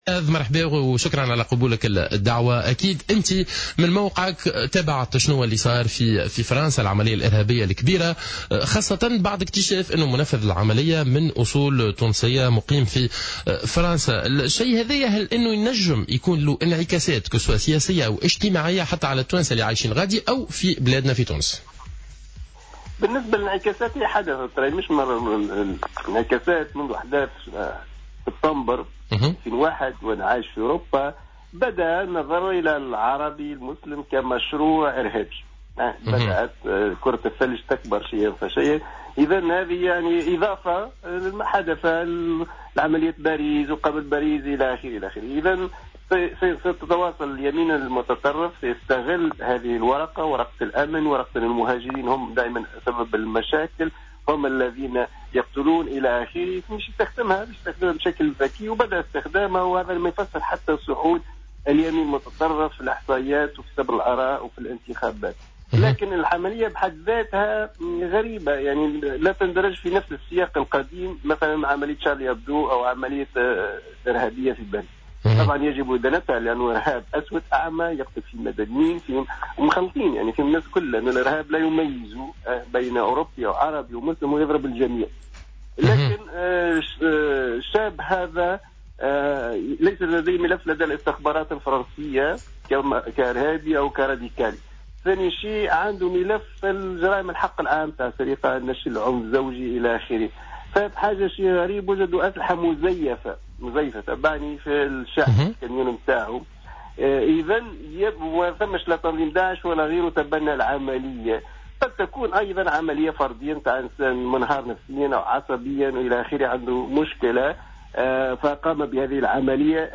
وقال في مداخلة له اليوم على "الجوهرة أف أم" إنه بدأ ينظر للعربي المسلم منذ أحداث سبتمبر كمشروع إرهابي، مضيفا أن اليمين المتطرّف في أوروبا أصبح يستغل هذه الأحداث بشكل ذكي للصعود إلى السلطة.